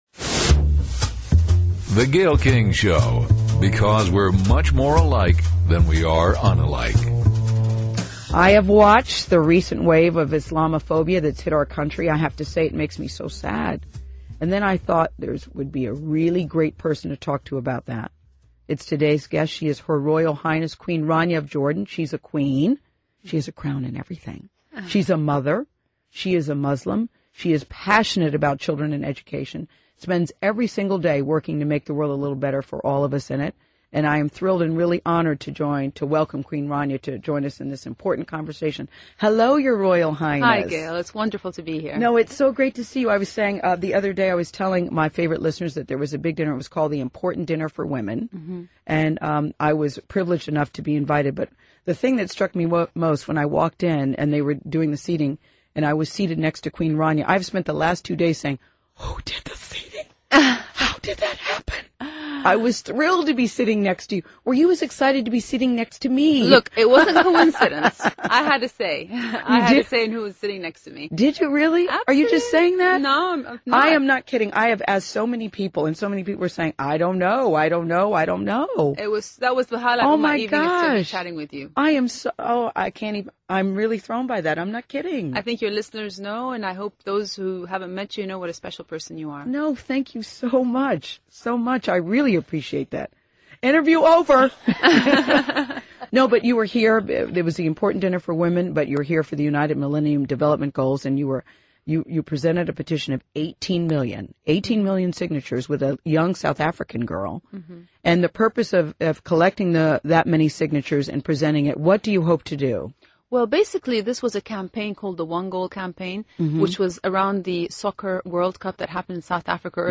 In Interview with Gayle King, Queen Rania Talks about Education and Tolerance | Queen Rania